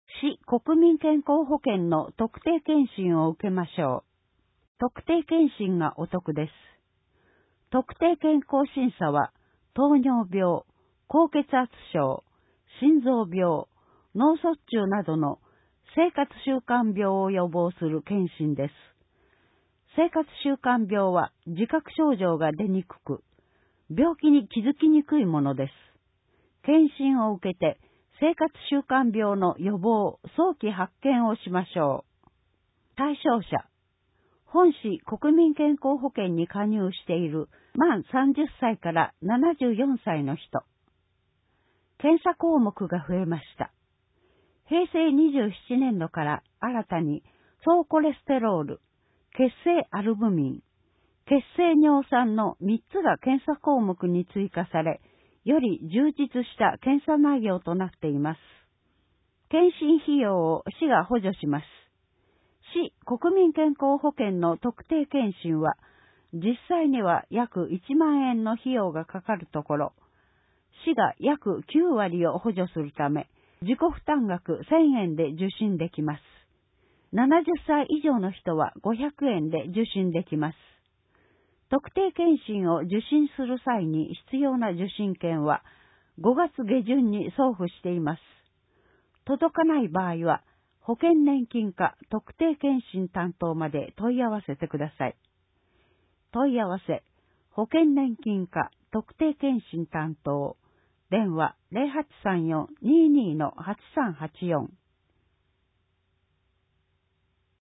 音訳広報
広報しゅうなんを、音読で収録し、mp3形式に変換して配信します。
この試みは、「音訳ボランティアグループともしび」が、視覚障害がある人のために録音している音読テープを、「周南視聴覚障害者図書館」の協力によりデジタル化しています。